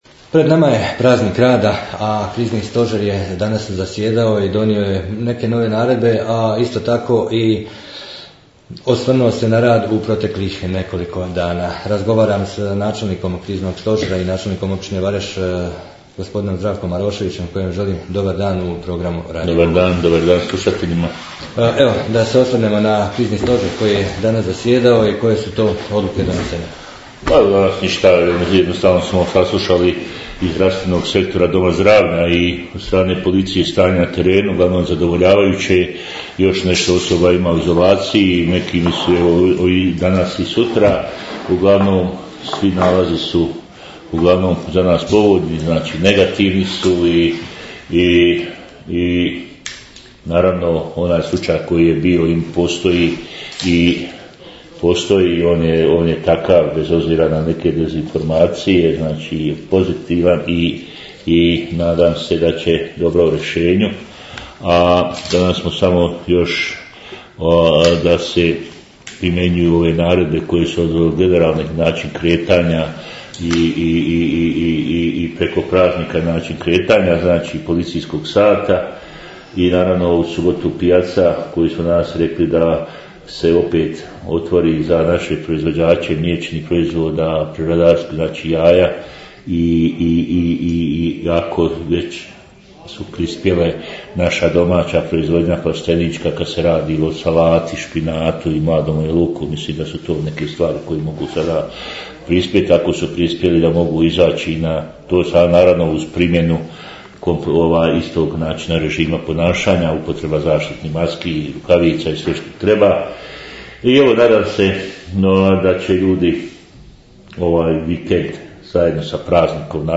O stanju u općini Vareš i novim naredbama Kriznog stožera razgovarali smo s načelnikom Zdravkom Maroševićem, poslušajte...